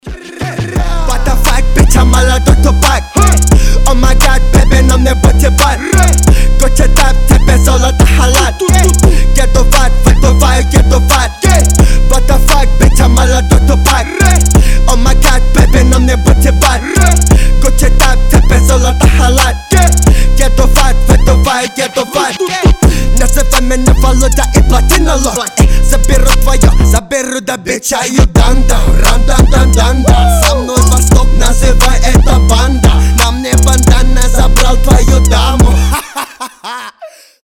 жесткие
мощные басы
взрывные
смех
злые
страшные
грубые